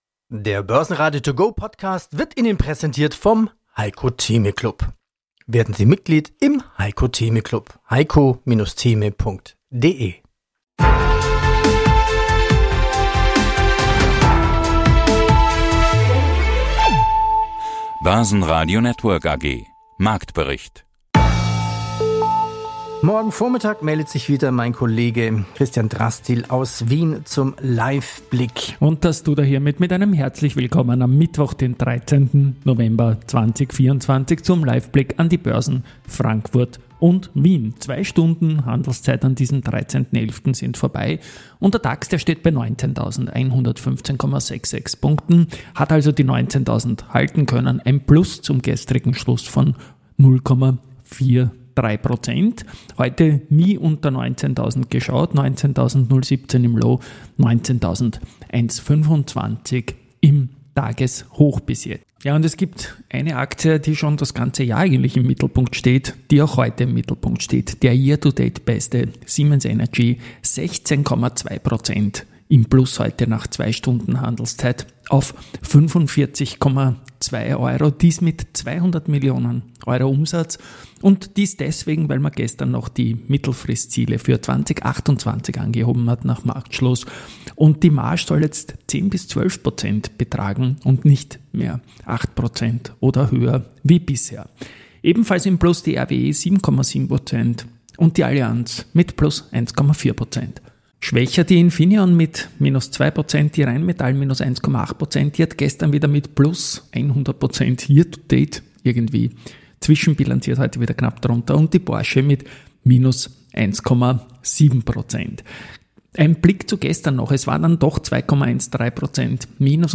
Die Börse zum hören: mit Vorstandsinterviews, Expertenmeinungen und Marktberichten.